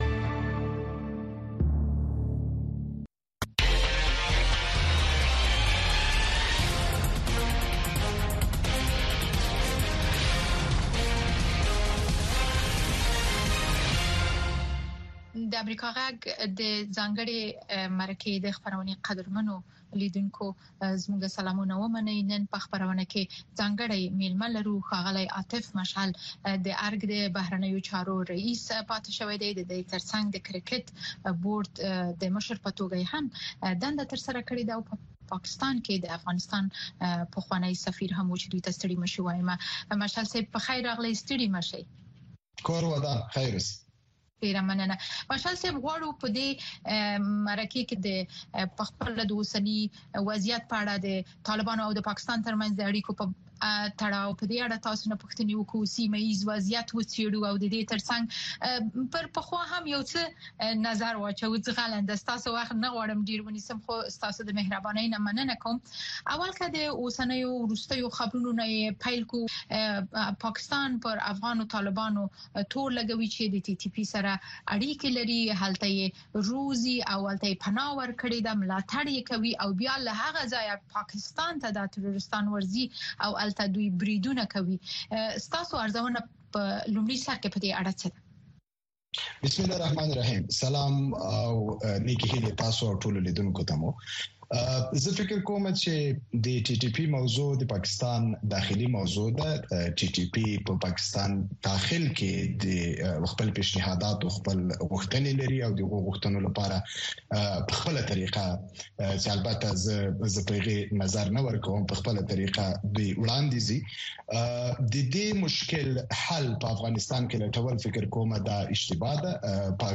ځانګړې مرکه